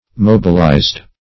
Mobilize \Mob"i*lize\, v. t. [imp.